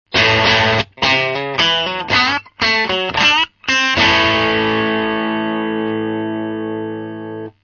(NoEQ,NoEffectで掲載しています）
No.3 MP3 Mavisパッチをエフェクタボード内で二本使ったサウンドです。
さすがにパッチ二本、弾いてて全然パワー落ちてるのが解ります。
弾いてて疲れる、というのでしょうか、手元で付けたニュアンスが出にくい音と感じました。